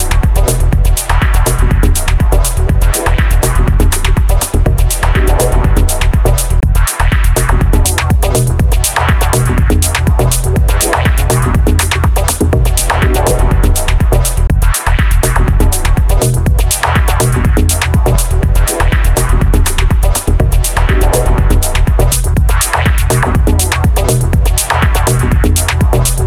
first you listen a loop from Digitakt’s main out, then I crossfade in Ableton to the individual output mix… then I go back and forth a few times. you can tell the difference, but I think that with some proper mixing it can sound pretty close (and better too)